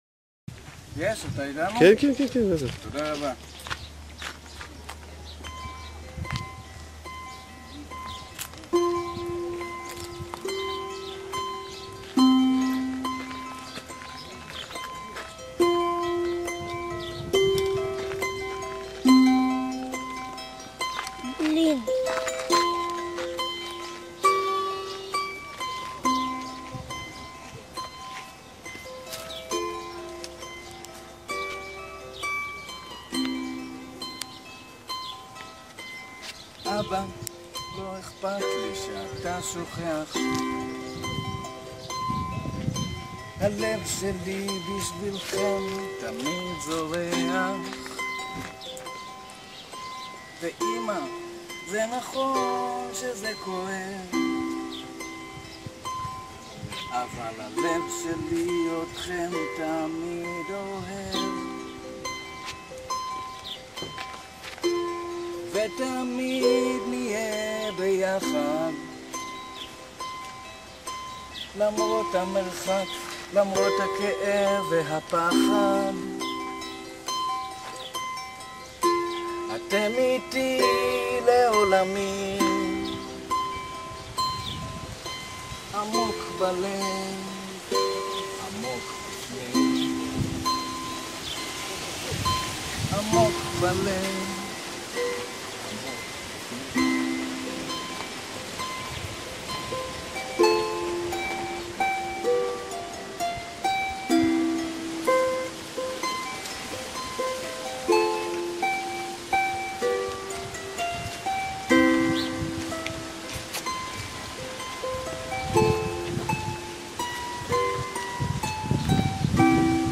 השיר הראשון שכתבתי עם הנבל.